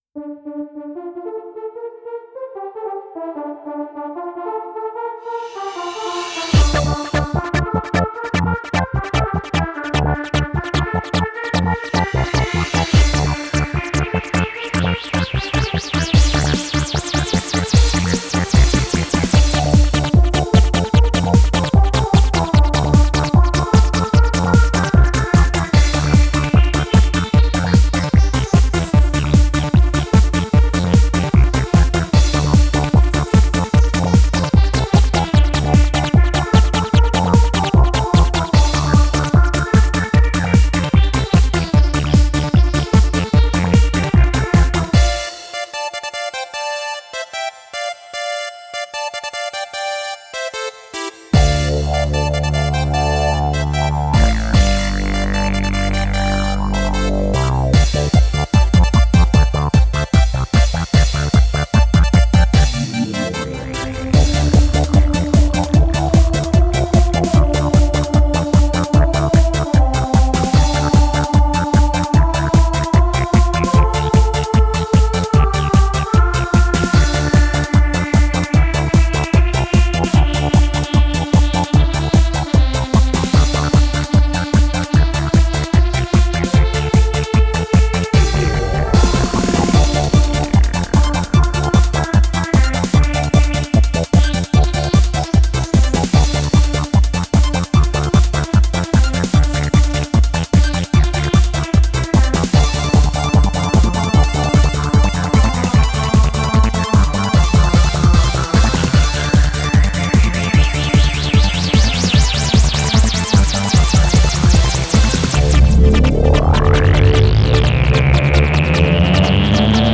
Synths meet silicon chips between France and England